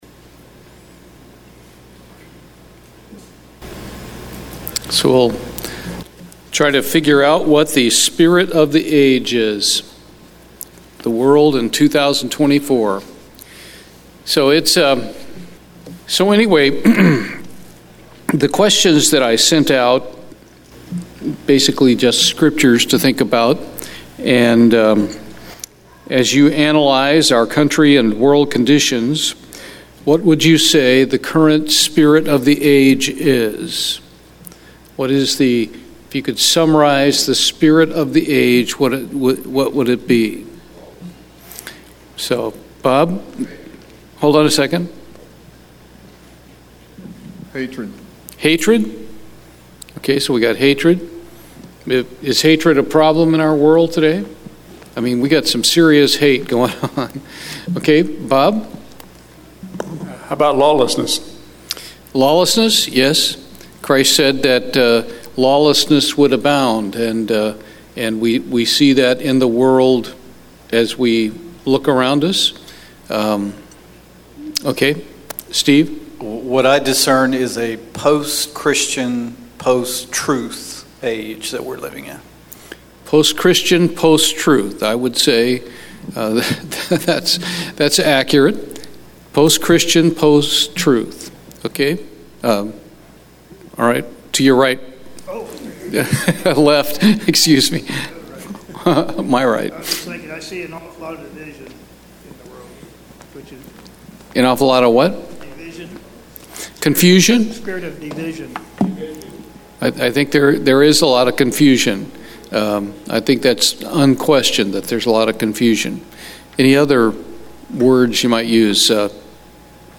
Bible Study, The spirit of the age